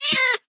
minecraft / sounds / mob / cat / hitt1.ogg